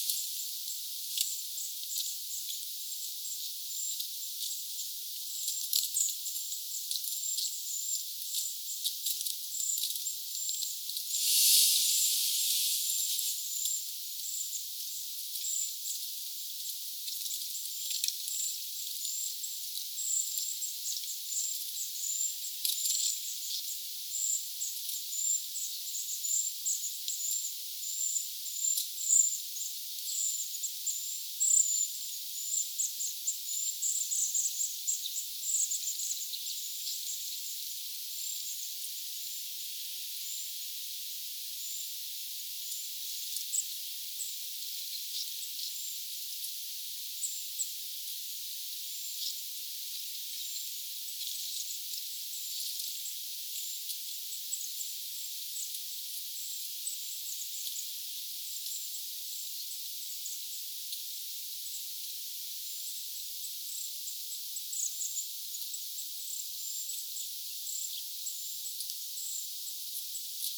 ilmeisesti kaksi puukiipijälintua
ääntelee toisilleen?
olisiko_siina_kaksi_puukiipijalintua.mp3